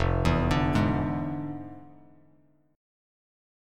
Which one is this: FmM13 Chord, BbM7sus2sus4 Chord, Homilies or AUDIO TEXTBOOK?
FmM13 Chord